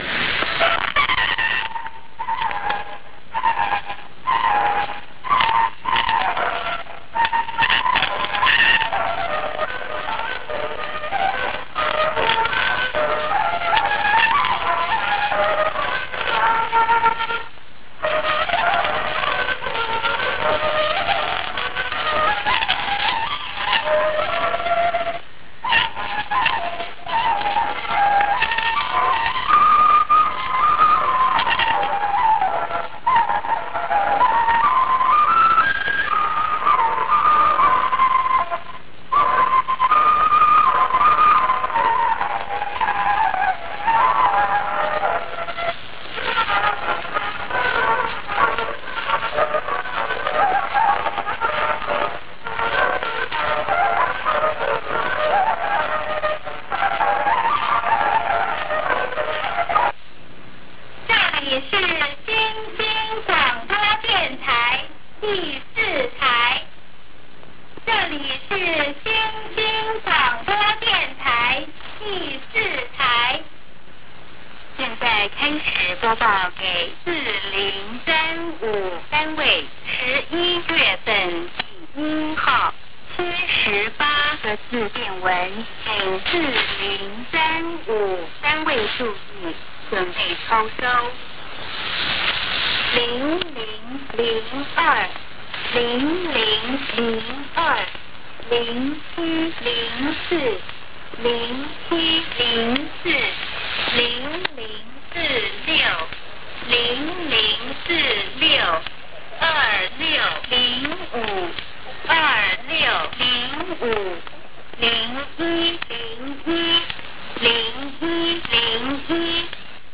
It features fancy presenters, hip music, and cool announcements like "We wish you health and happiness," to get you through the day.
Transmissions usually start with a piece of traditional Chinese music , featuring a flute.
Usually two women are featured: one for the general announcements, the other for the messages. They are both pre-recorded and machine generated and this clearly shows in the coded messages which suffer from lack of proper intonation.
This announcement is repeated one or two times, with the interval music in between.